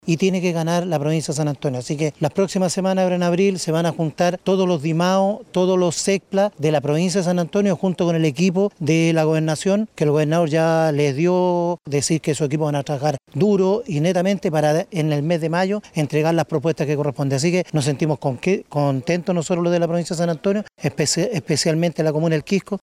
Por su parte, José Jofré, alcalde de El Quisco y presidente de la Asociación de Municipalidades de la Provincia de San Antonio, detalló que durante el próximo mes de abril se realizarán más reuniones municipales para entregar las propuestas que corresponden.
cu-reunion-el-molle-alcalde-el-quisco-1.mp3